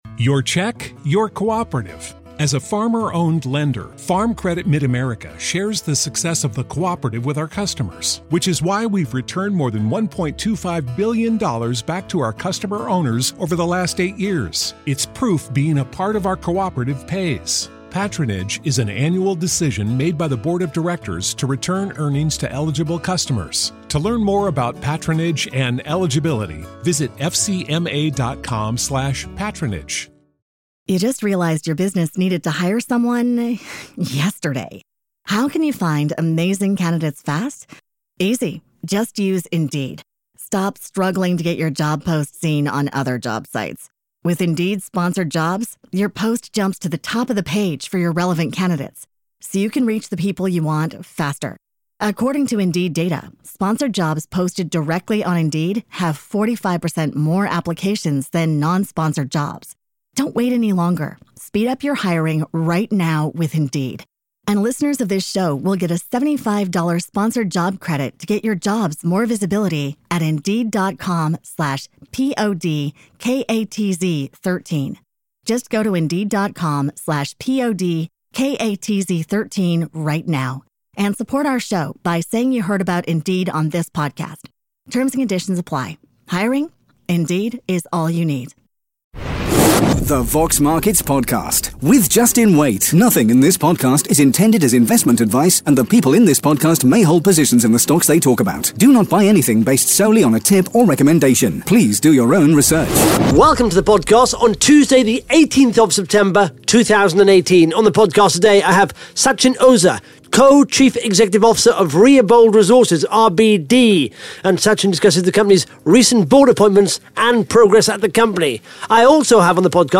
(Interview starts at 1 minute 47 seconds)